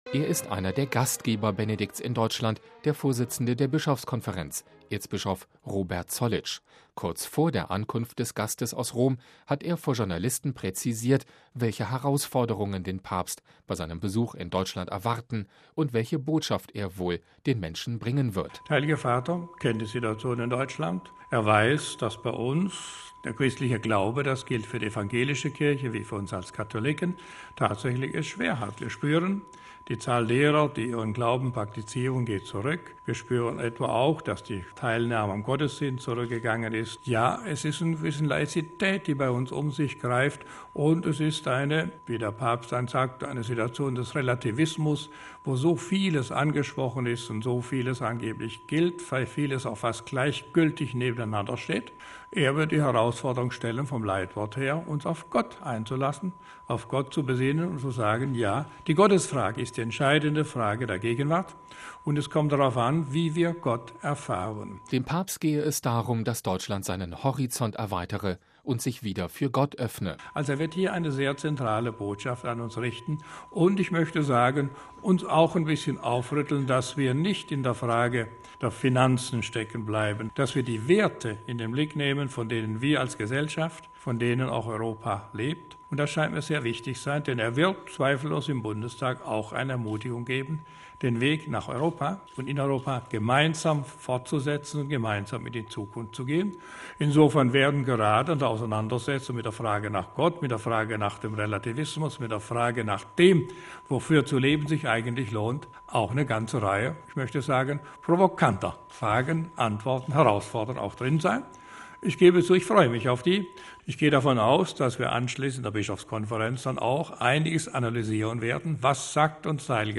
Kurz vor der Ankunft des hohen Gastes aus Rom hat er vor Journalisten präzisiert, welche Herausforderungen den Papst bei seinem Besuch in Deutschland erwarten und welche Botschaft er den Menschen bringen wird.